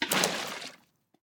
Minecraft Version Minecraft Version 25w18a Latest Release | Latest Snapshot 25w18a / assets / minecraft / sounds / item / bucket / empty_fish2.ogg Compare With Compare With Latest Release | Latest Snapshot
empty_fish2.ogg